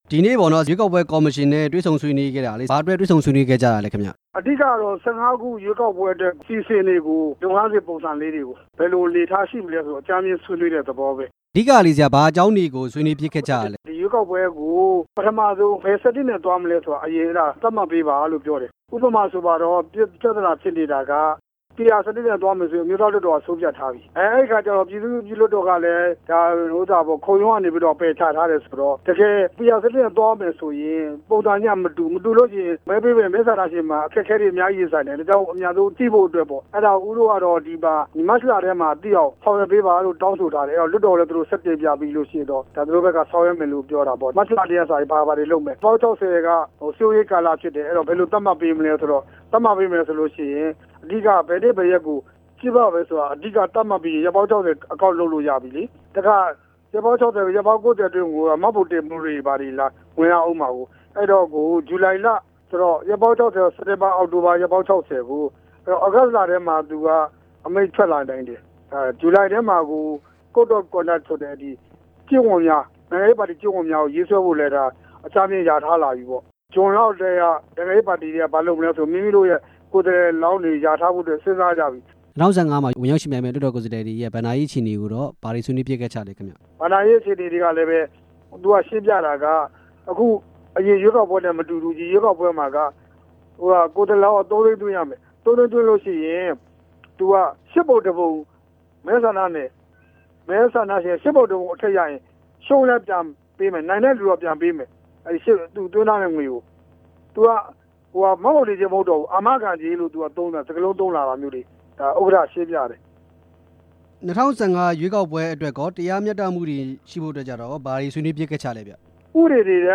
၂၀၁၅ အထွေထွေရွေးကောက်ပွဲ နဲ့ ပတ်သက်ပြီး မေးမြန်းချက်